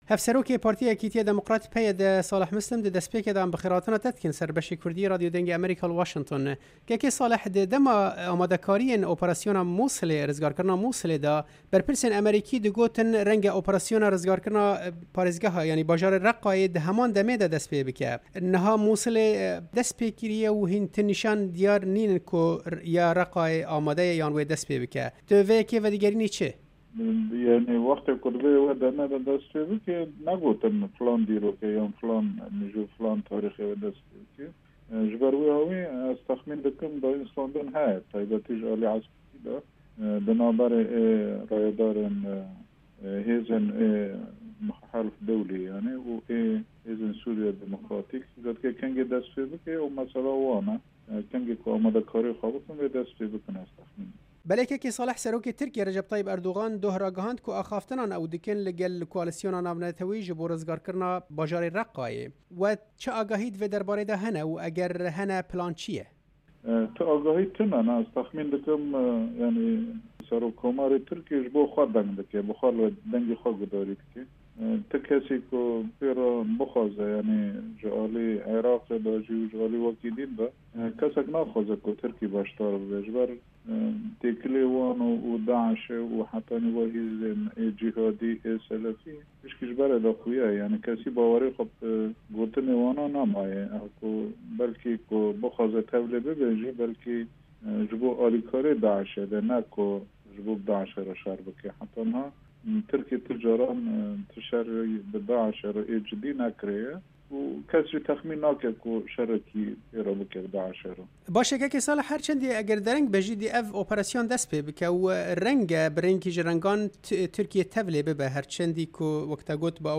Hevserokê Partiya Yekîtiya Demukrat PYD Salih Mislim, di hevpeyvînekê de li gel Dengê Amerîka ev babet şîrove kir û got: